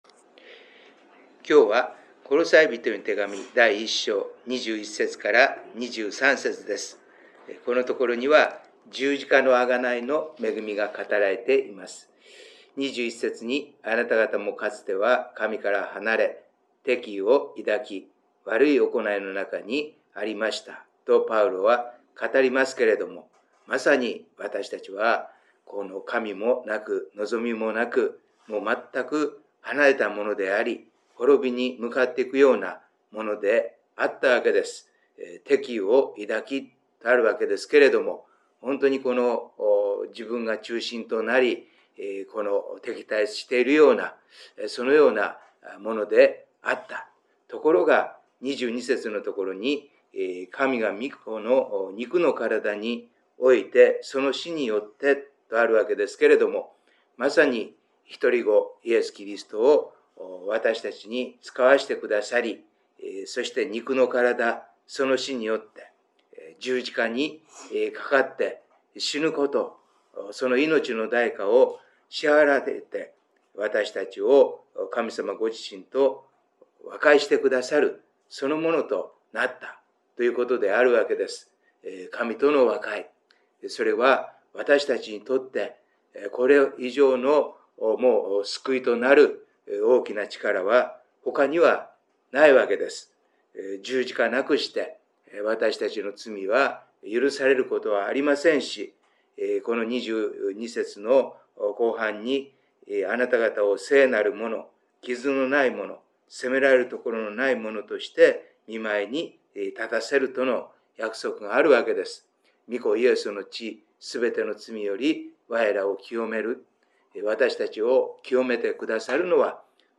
オーディオ礼拝メッセージです。